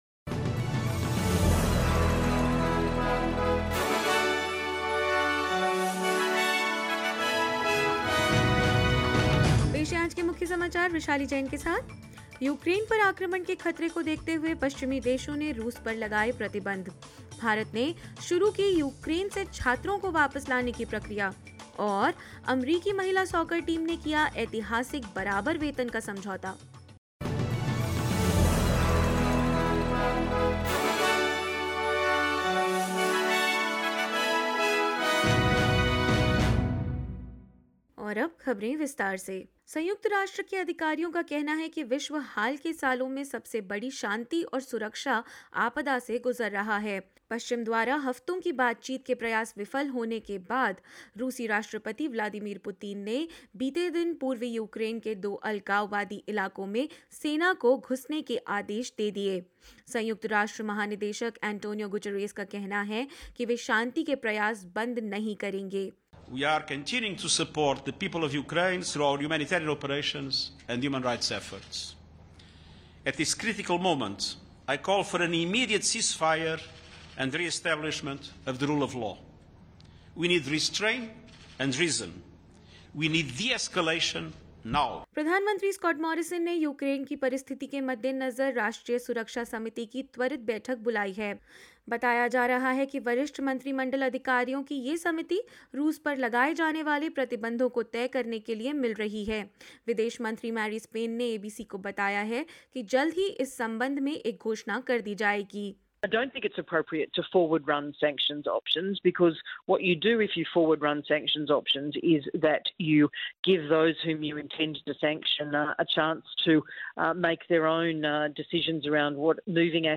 hindi_news_2302_final.mp3